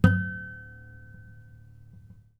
ostinato_and_interrupt / samples / strings_harmonics / 2_harmonic / harmonic-10.wav
harmonic-10.wav